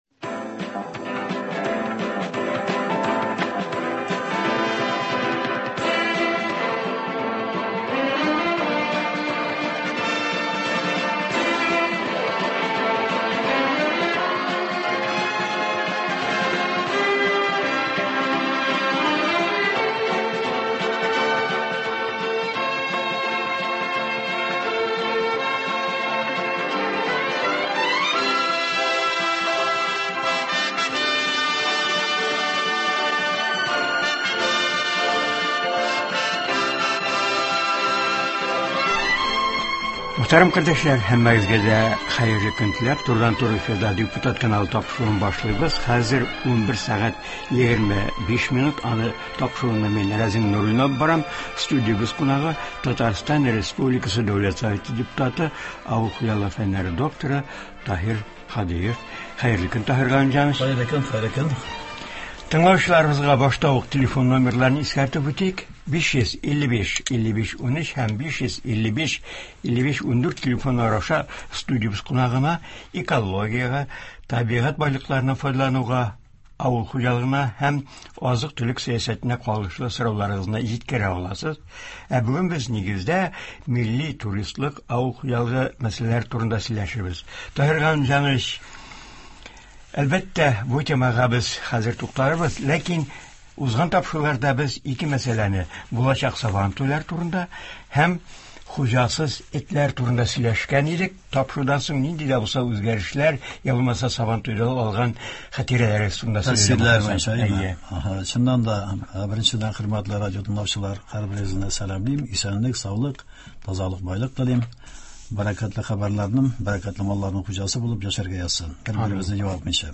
Республикабызда милли туризмны үстерү өчен нинди шартлар бар? Болар хакында үзенең уй-фикерләре белән Татарстан республикасы Дәүләт Советы депутаты, авыл хуҗалыгы фәннәре докторы Таһир Һадиев уртаклаша, тыңлаучыларны кызыксындырган сорауларга җавап бирә.